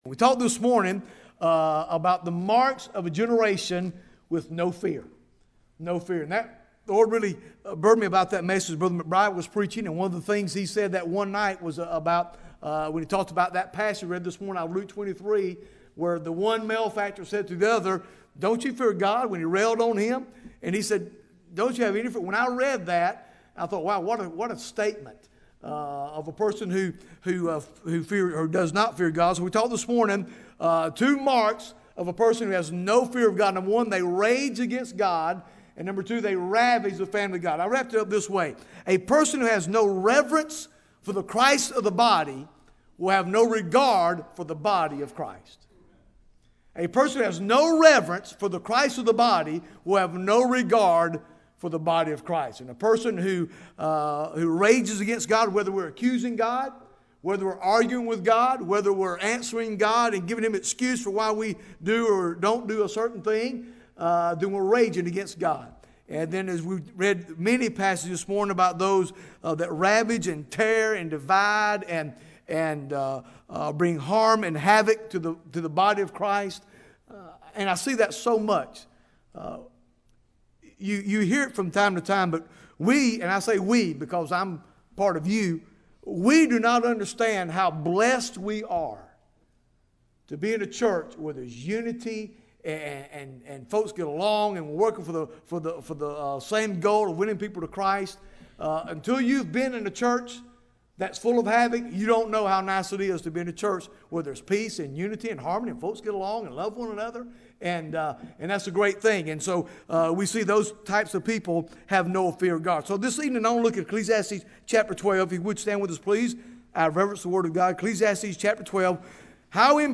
Bible Text: Ecclesiastes 12 | Preacher